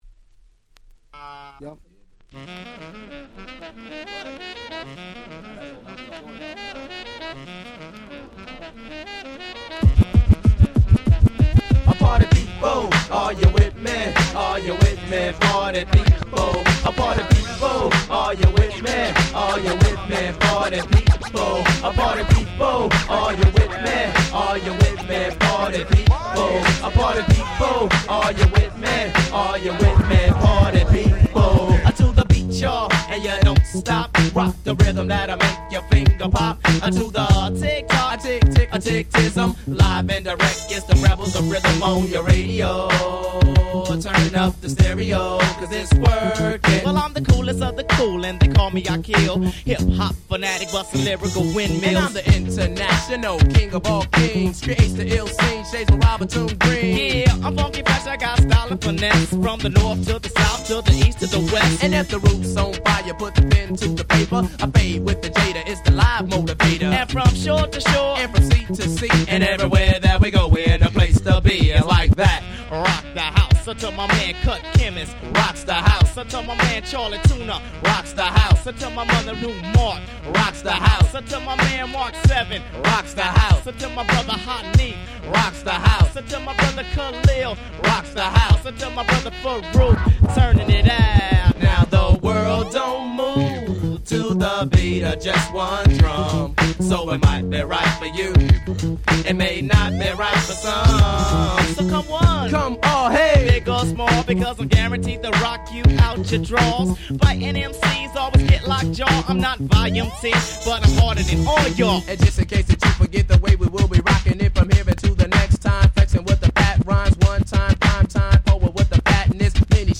95' Underground Hip Hop Super Classics !!